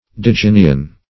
Search Result for " digynian" : The Collaborative International Dictionary of English v.0.48: Digynian \Di*gyn"i*an\, Digynous \Dig"y*nous\, a. [Cf. F. digyne.]